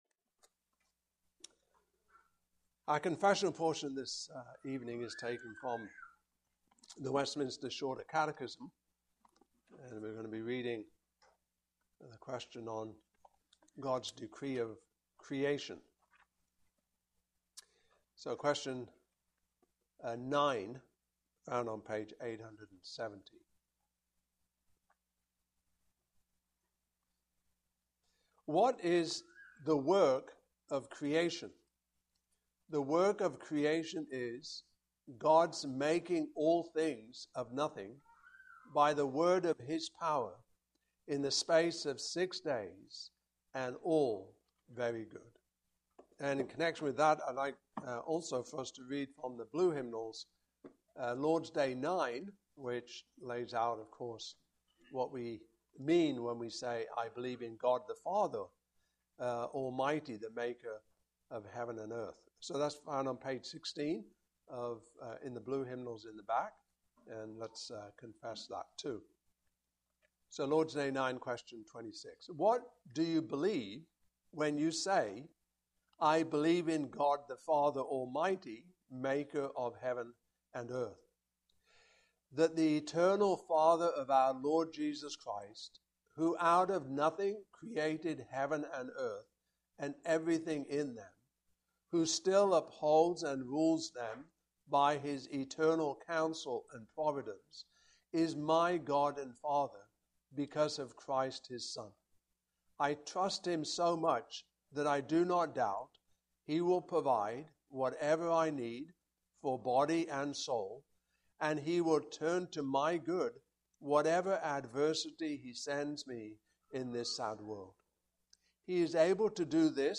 Westminster Shorter Catechism Passage: Genesis 1:1-2:3 Service Type: Evening Service Topics